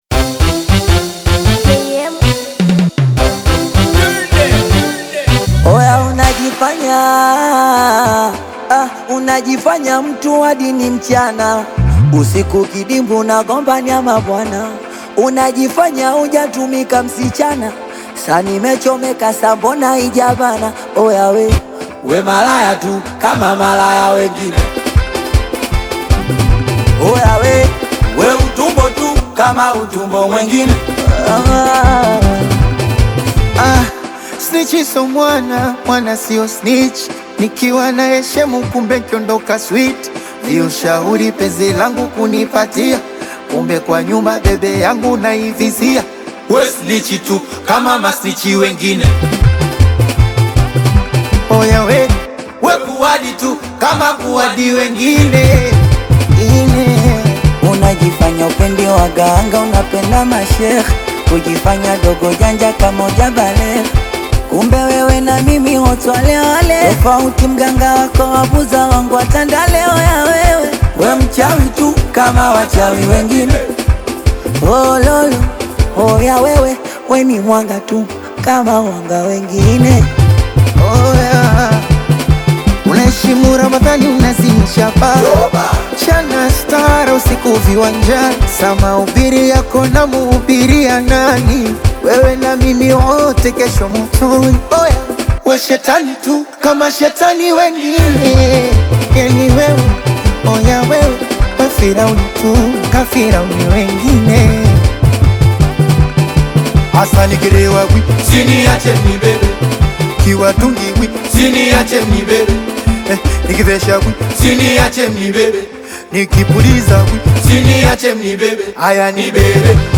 Singeli, Bongo Fleva, Amapiano, Afro Pop and Zouk